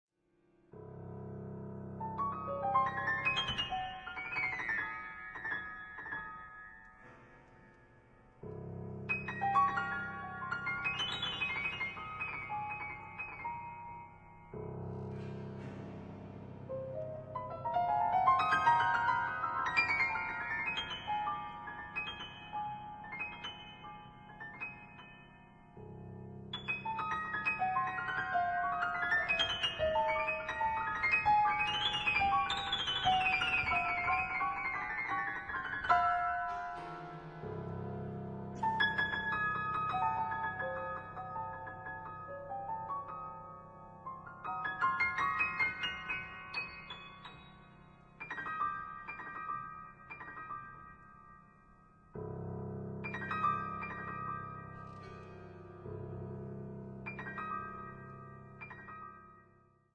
Composer, conductor, band leader, jazz pianist